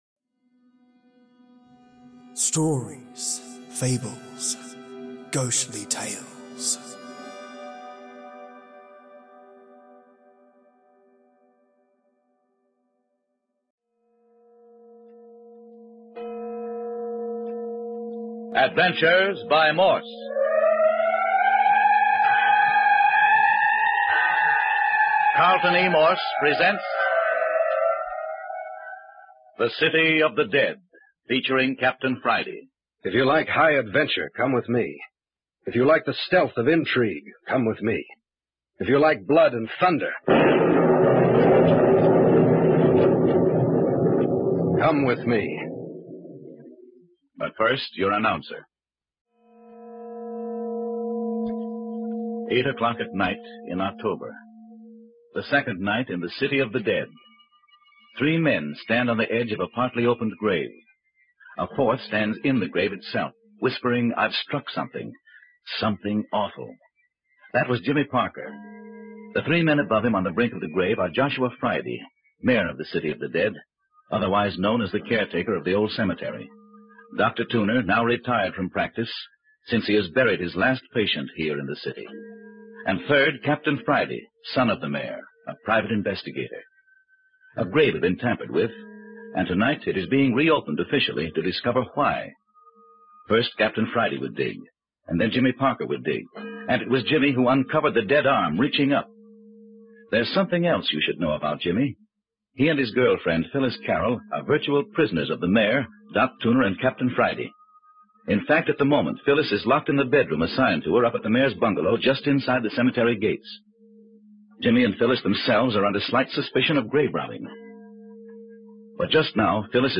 😋 A 10 part series of Audio Drama straight from the "Adventures of Morse" series. Today I bring you remastered parts 3 and 4, where more bodies are uncovered, the crazy yelling man has returned, and the Mayor holds the couple of not so innocent youngins hostage.